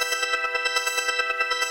SaS_MovingPad04_140-E.wav